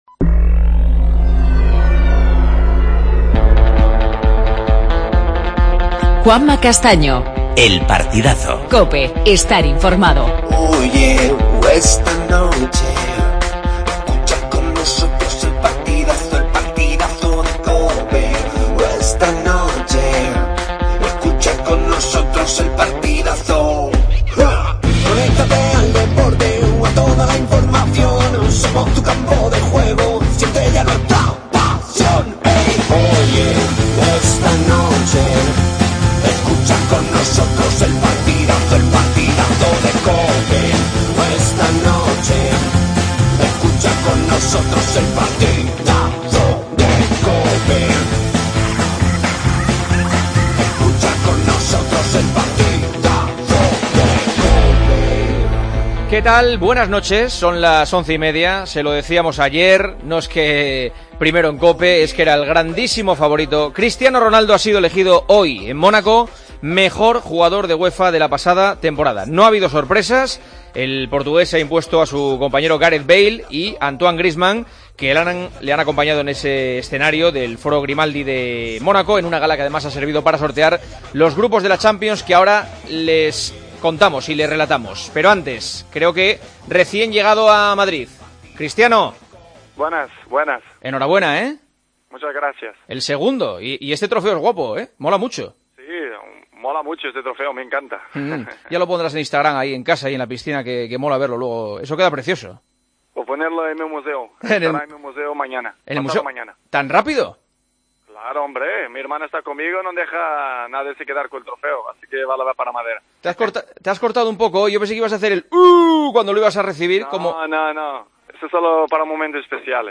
Se conocen ya los grupos de la Fase de Grupos de la Champions. Cristiano Ronaldo nombrado mejor jugador de Europa. Entrevista a Cristiano Ronaldo.